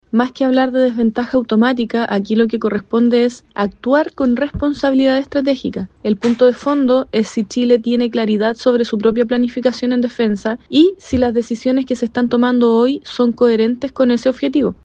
Desde la oposición, la diputada Éricka Ñanco (FA) afirmó que el tema de fondo es definir una estrategia clara en defensa nacional, por lo que no considera que aplique la desventaja tras la millonaria adquisición de Perú.